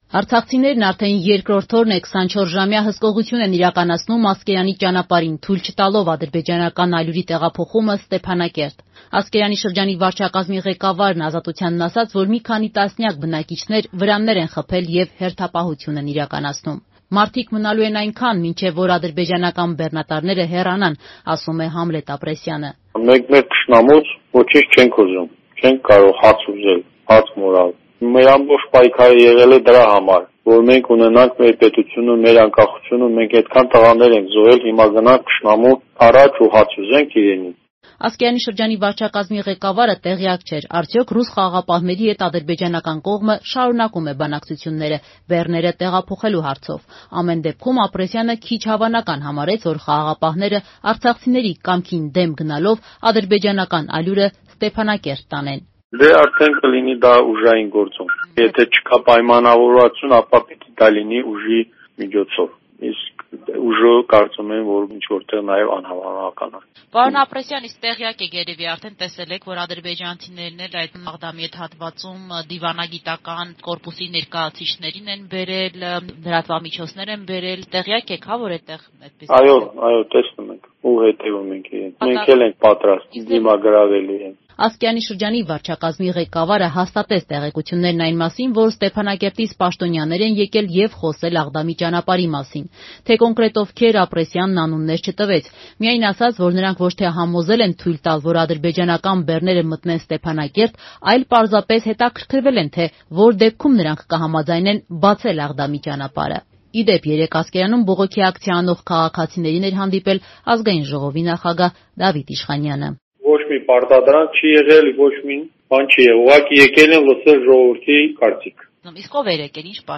«Ռուս խաղաղապահների հետ ժողովրդի հարաբերությունները լարվել են». արցախցի լրագրողը մանրամասներ է փոխանցում Ասկերանից
Ռեպորտաժներ